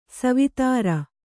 ♪ savitāra